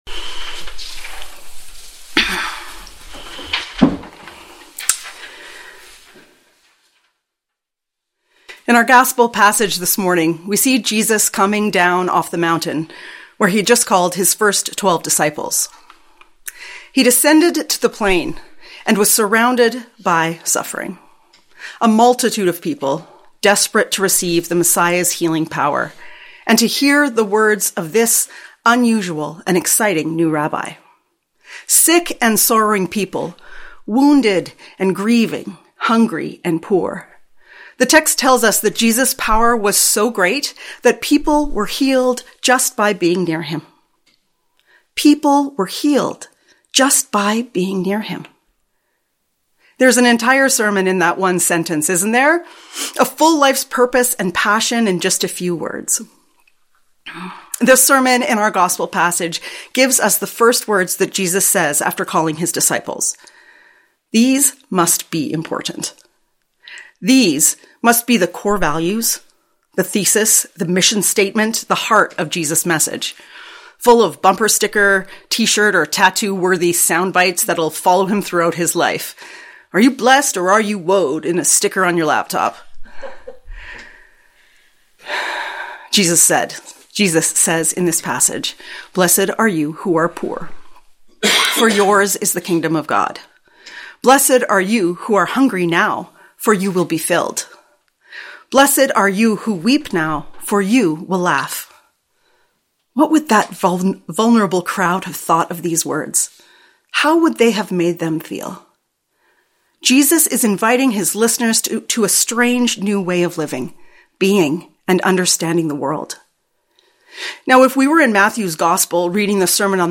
Sermon Podcast for First Baptist Church of Edmonton, Alberta, Canada